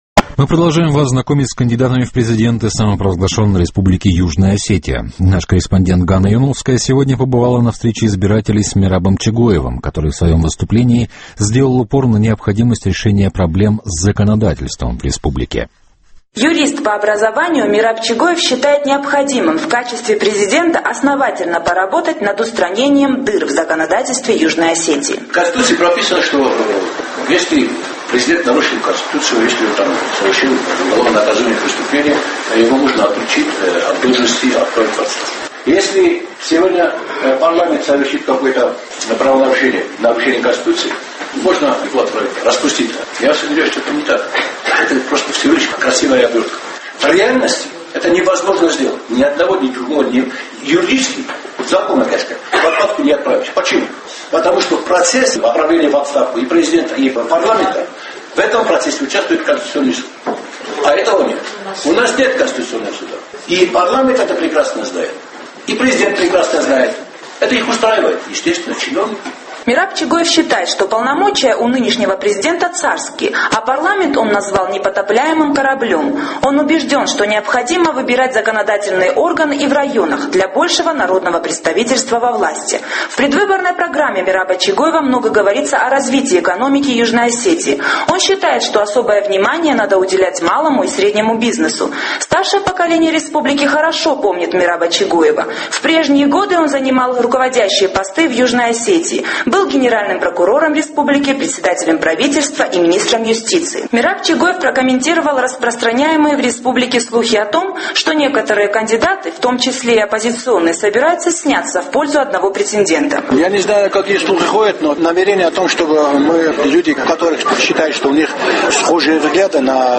Кандидат в президенты в своем выступлении сделал упор на необходимости решения проблем с законодательством в республике.